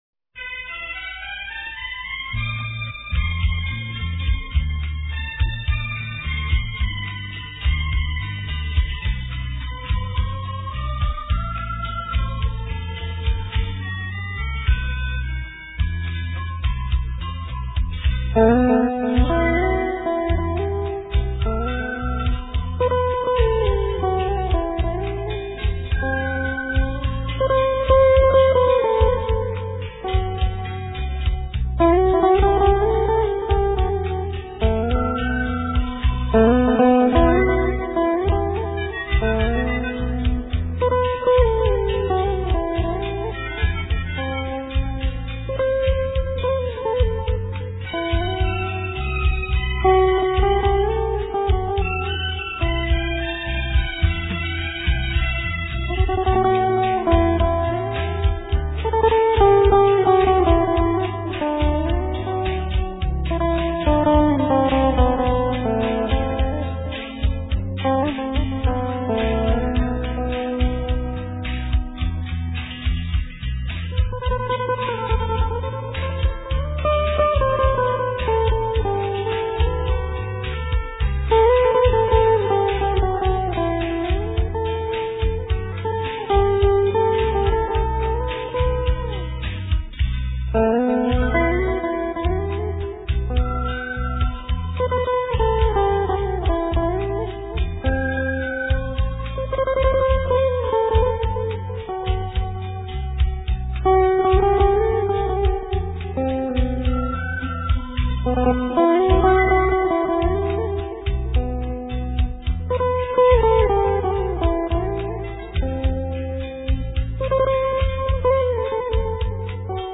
* Thể loại: Việt Nam